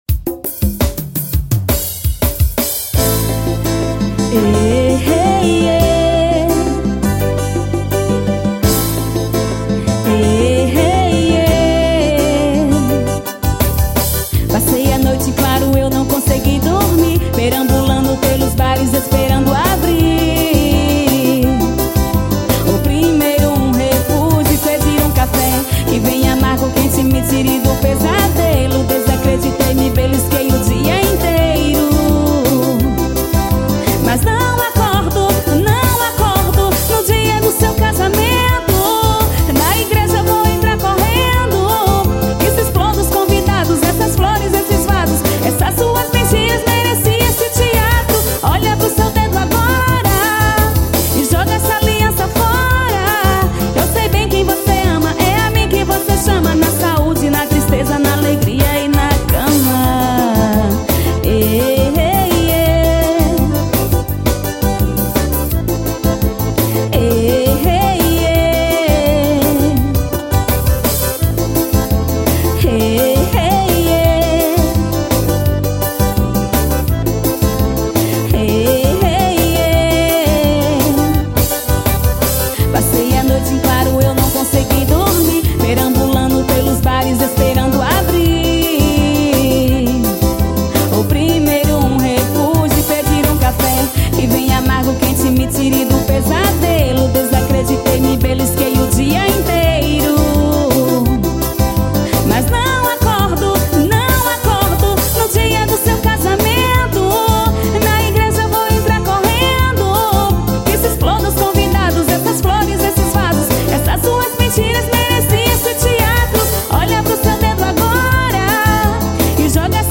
forro pegado.